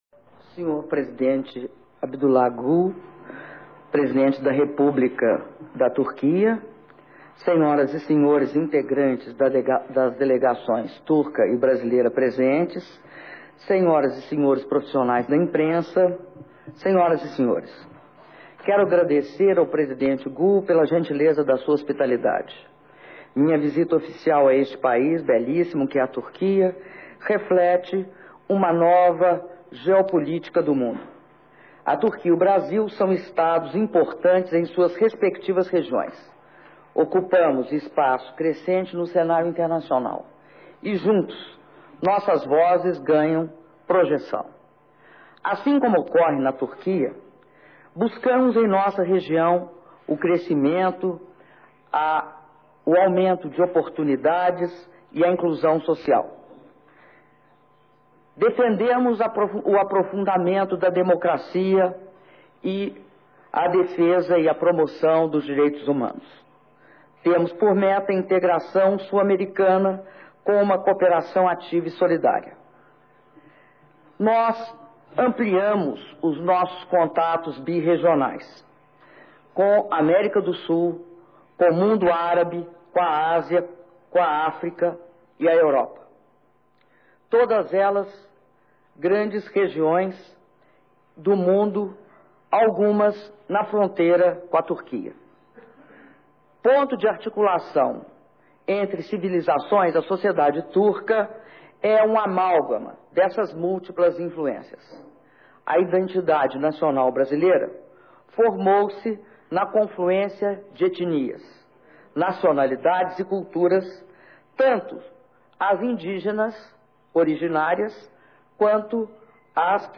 Declaração à imprensa da Presidenta da República, Dilma Rousseff, após cerimônia de assinatura de atos - Ancara/Turquia